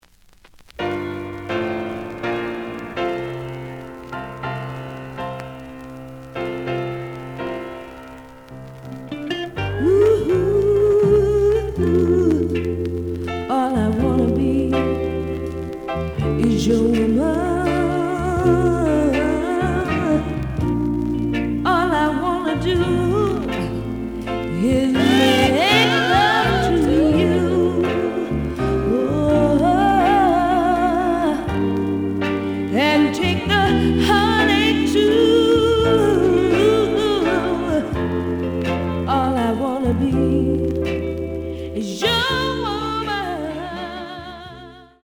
The audio sample is recorded from the actual item.
●Genre: Soul, 70's Soul
Slight noise on both sides.